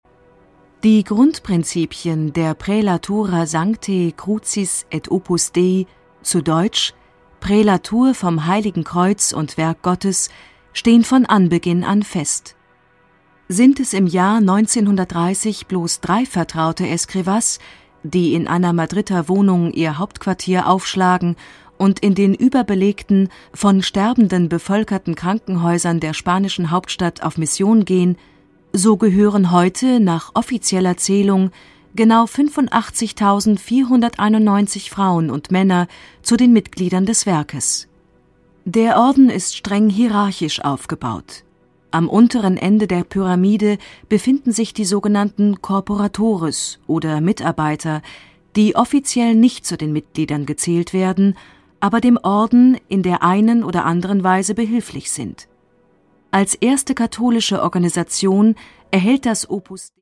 Produkttyp: Hörbuch-Download
Wie mächtig und gefährlich ist Opus Dei tatsächlich? Ein fesselndes Feature zu einem der umstrittensten katholischen Orden, vielstimmig inszeniert mit mehreren Sprechern - diese Dokumentation bringt Erstaunliches ans Licht ...